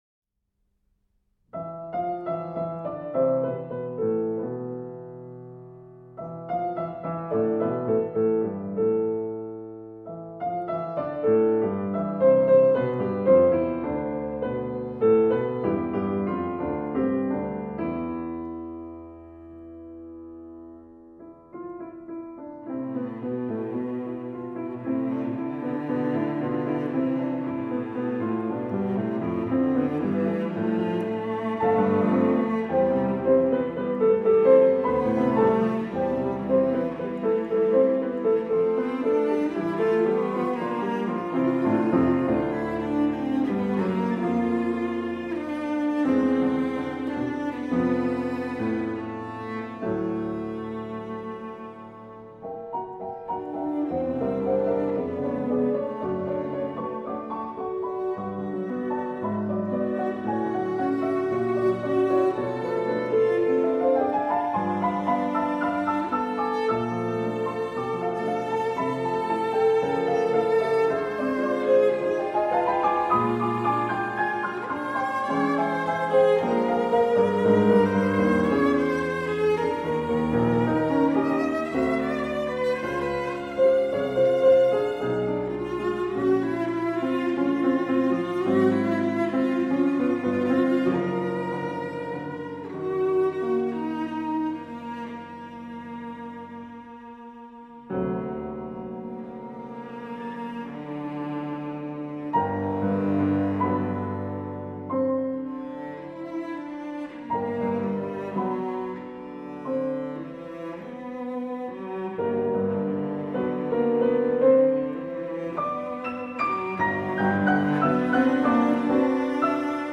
For Cello and Piano. Allegro moderato